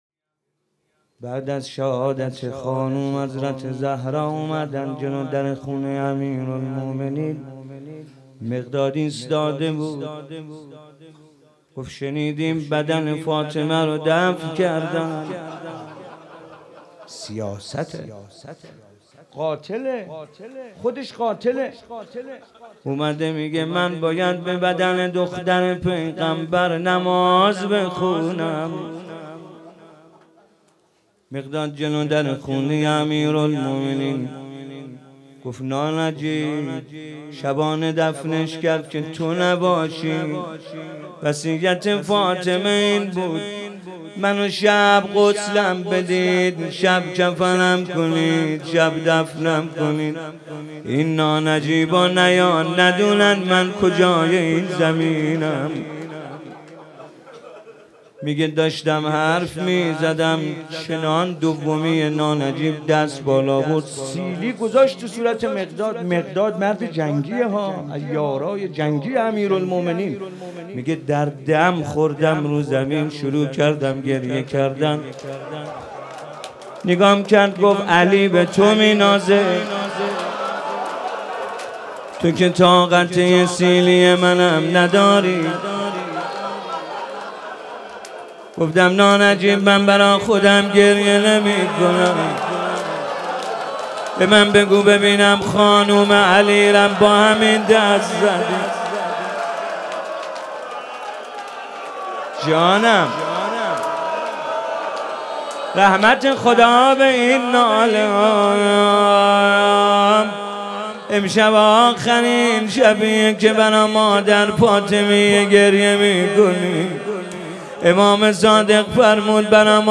روضه - هیئت هفتگی